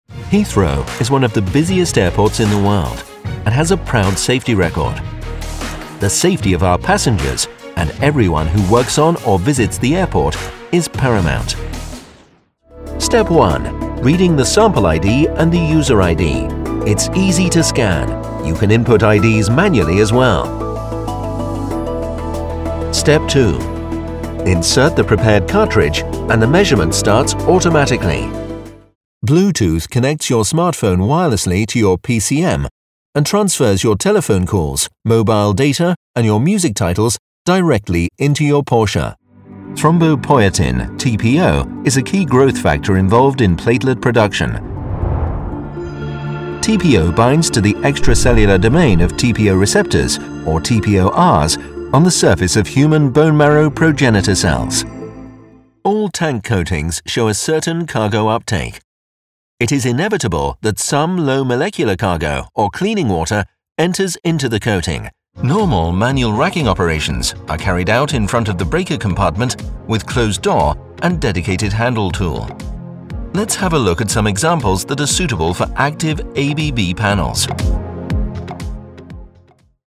English (British)
E-learning
Middle-Aged
BaritoneBassCountertenorDeep
WarmReassuringApproachableConversationalTrustworthyAuthoritativeBelievableIntelligent